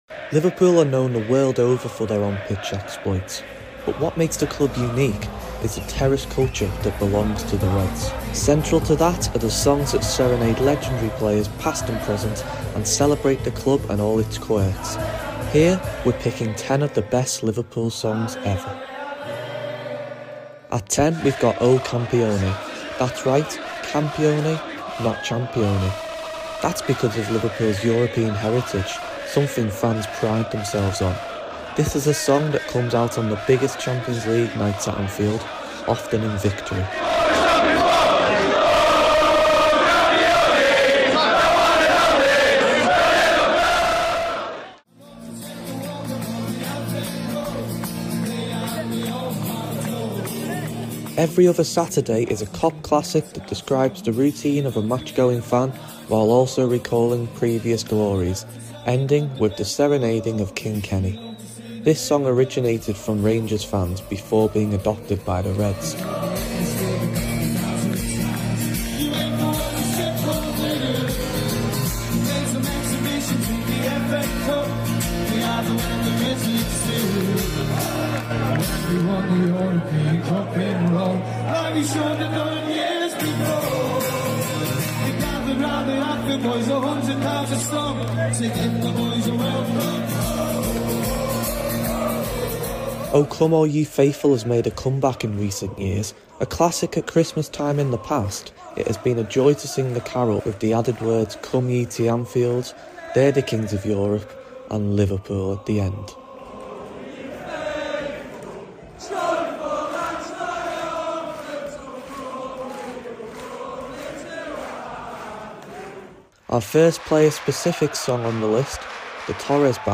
Liverpool FC songs & chants sound effects free download